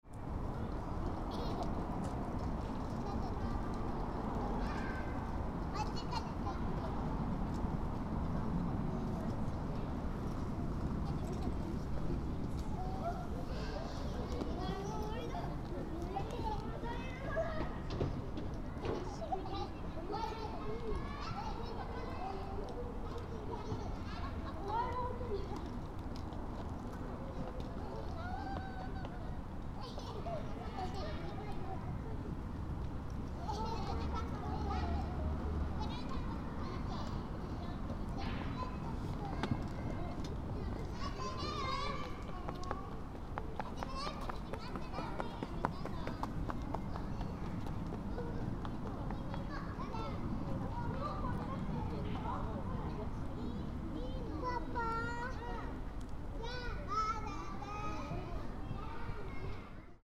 In the warm sunshine, many children were playing in the park. ♦ The birdsong was scarcely to be heard.